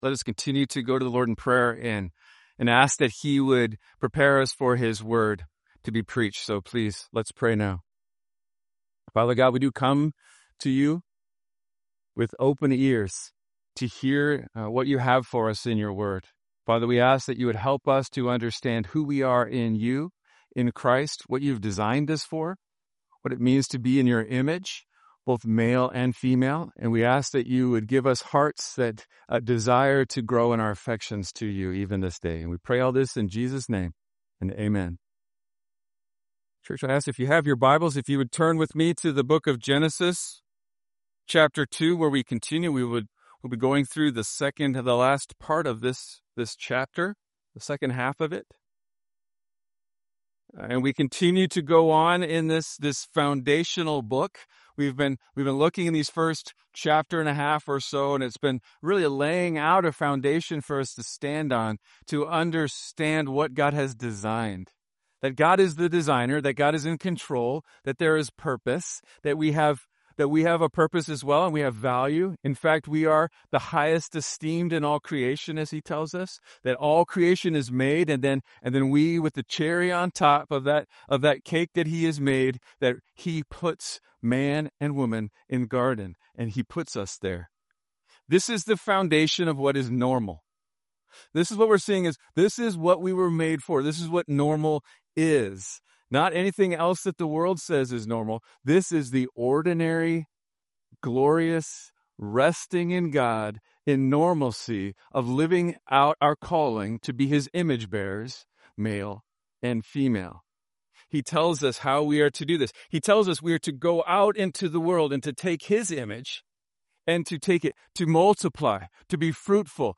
SERMON OUTLINE “The First Marriage” Genesis 2:18-25 Big Idea: Without Christ, women are unable to fulfill God’s mandate to live as helpers in all godliness. In Christ, she can do all things for God’s glory.